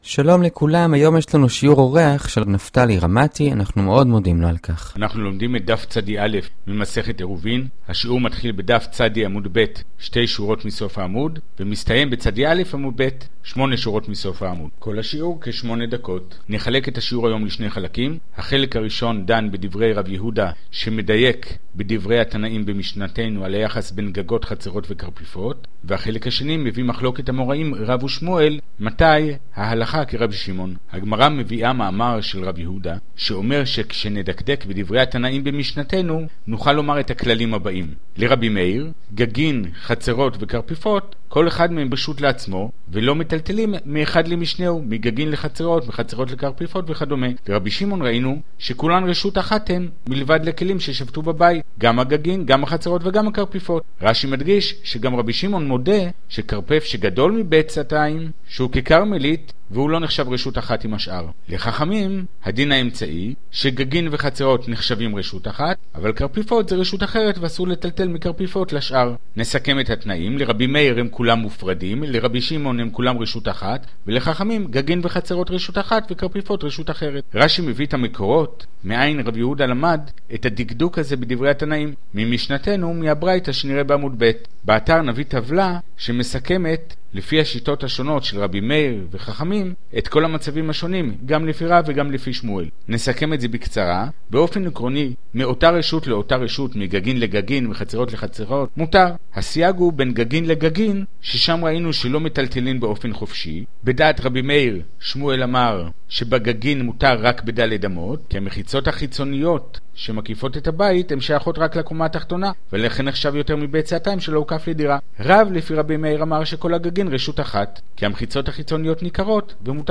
שיעור גמרא פשוט וברור כפי שמעולם לא שמעת.